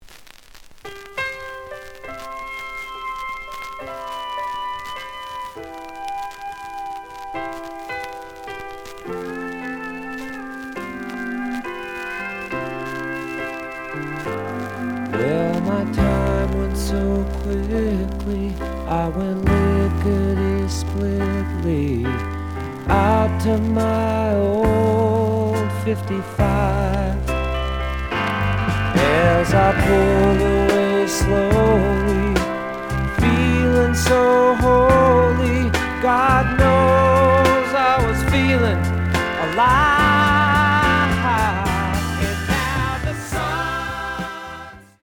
The audio sample is recorded from the actual item.
●Format: 7 inch
●Genre: Rock / Pop
Looks good, but slight noise on both sides.)